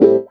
59 GIT 01 -L.wav